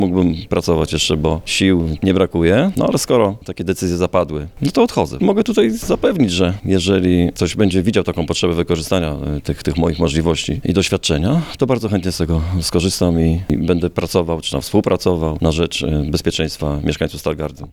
Dzisiaj, w Komendzie Powiatowej Policji w Stargardzie odbyło się uroczyste pożegnanie komendanta Leszka Ciarkowskiego.
Mam nadzieję, że moje doświadczenie jeszcze na coś się przyda – podkreślił były już komendant Powiatowej Komendy Policji w Stargardzie.
Ciarkowski o odejściu.mp3